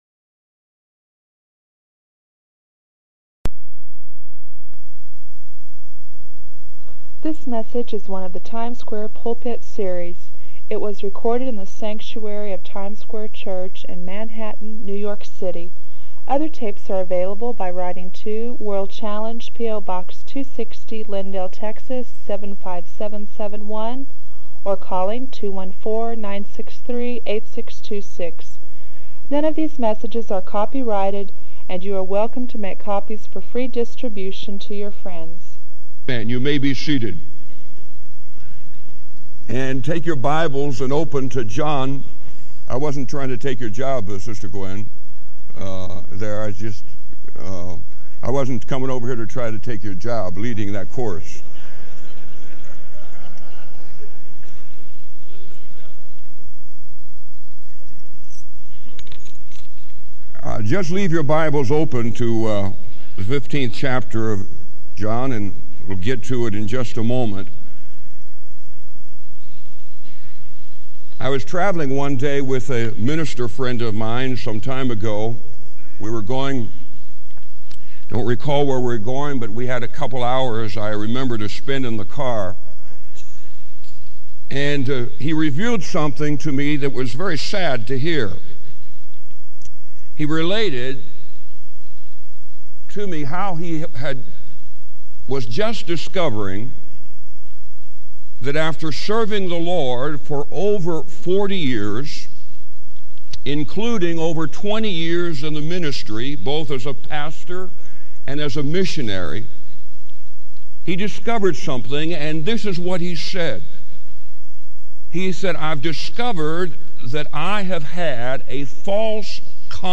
This sermon encourages listeners to embrace the friendship Jesus offers, moving beyond mere duty to joyful discipleship.